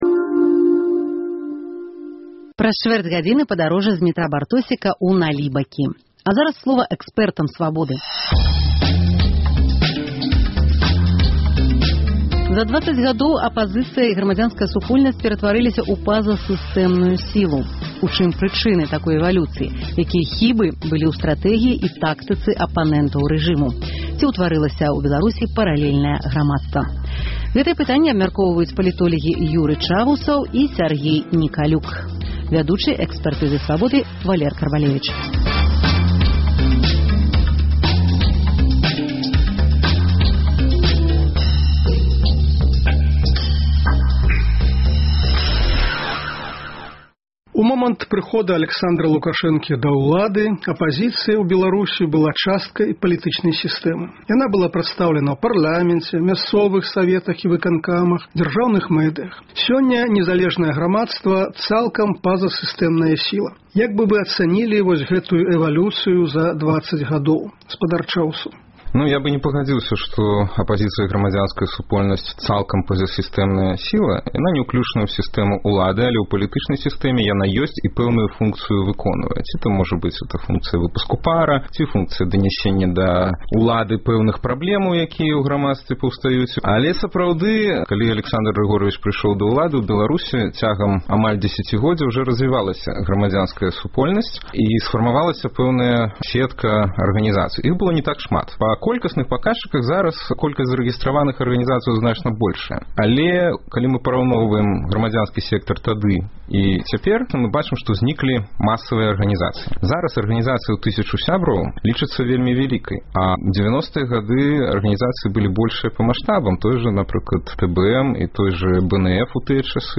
Абмеркаваньне гарачых тэмаў у студыі Свабоды.